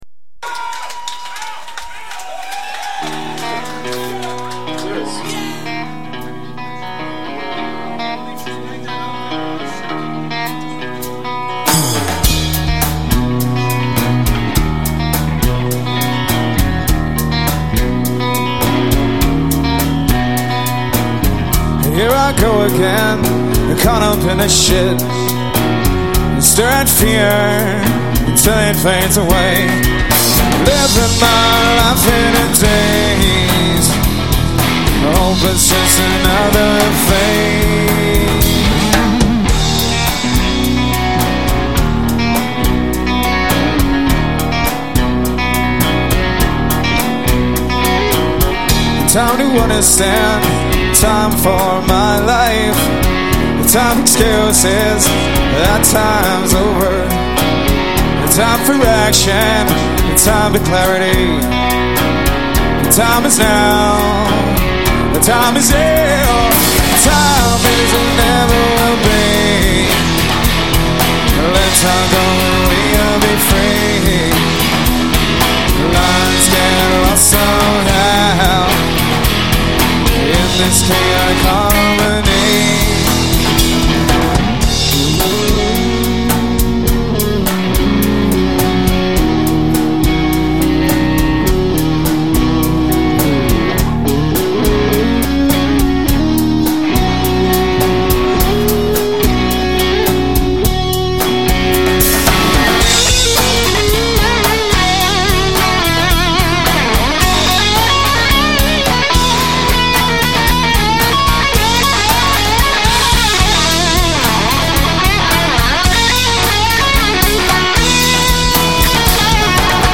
electric guitar
vocal ad libs in the middle